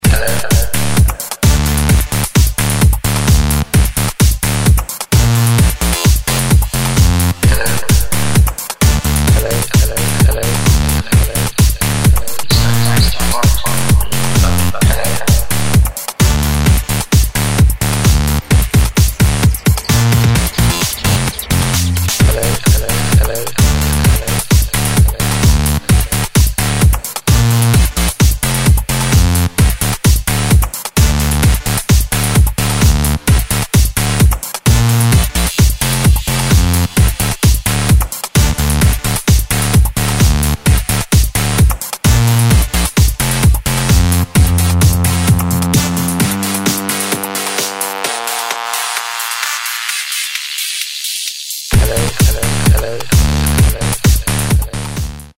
Список файлов рубрики :::::Короткие AAC/MP3 звонки:::::
электроника